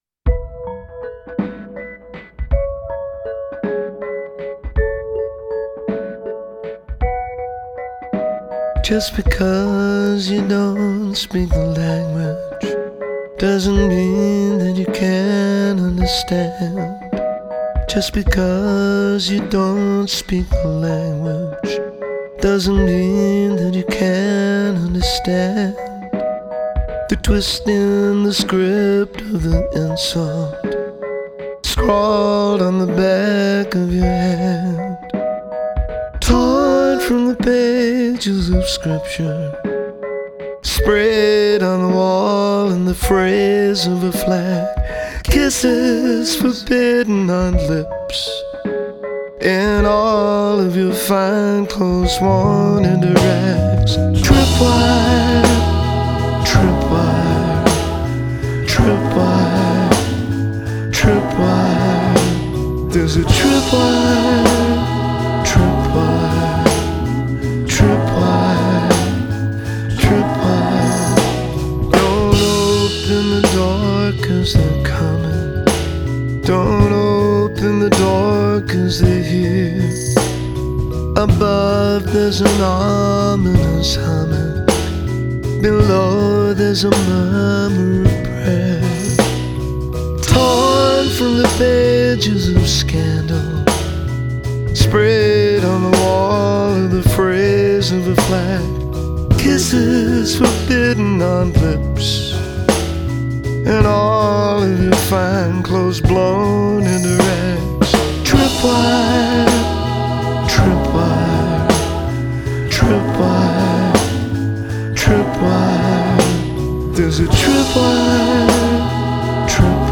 the funkiest backing music
’ the album’s silkiest tune.